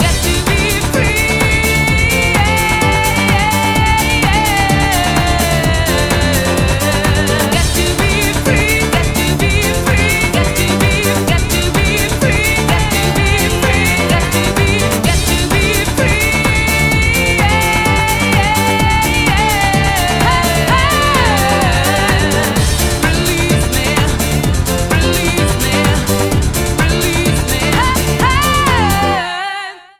евродэнс